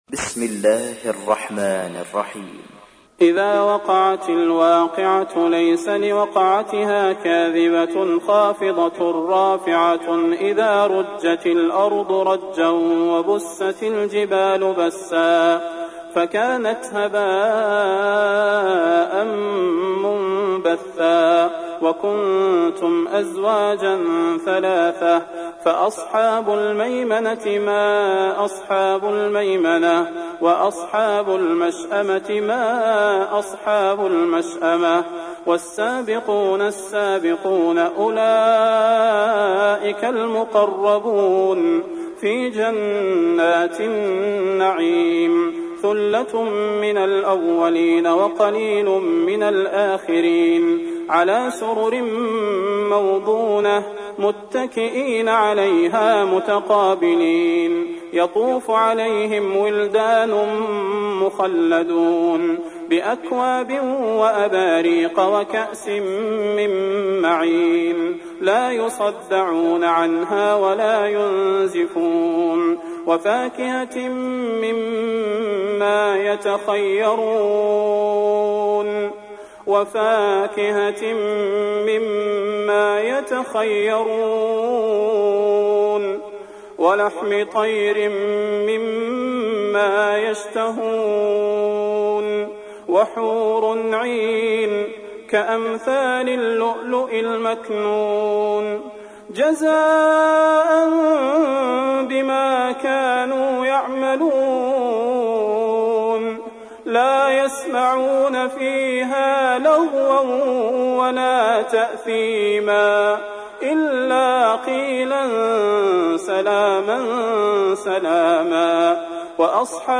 تحميل : 56. سورة الواقعة / القارئ صلاح البدير / القرآن الكريم / موقع يا حسين